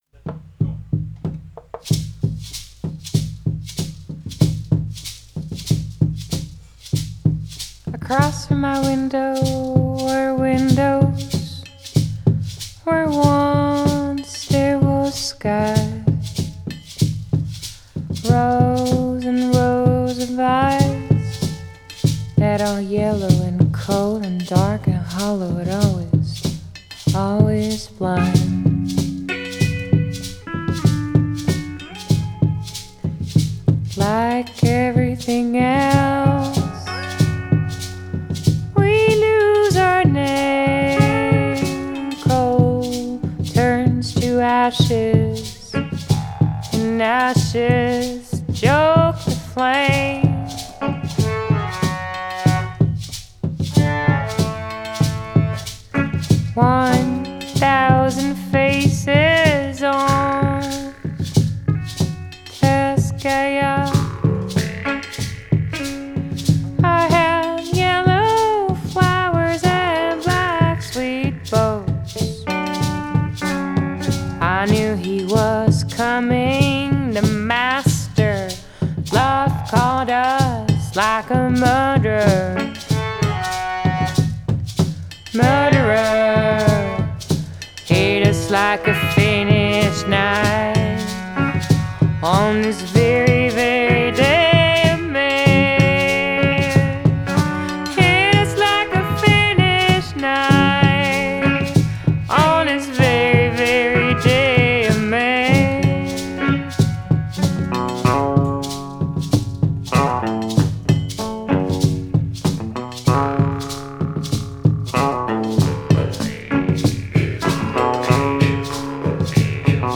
Genre: Folk, Indie, Country Folk, Female Vocal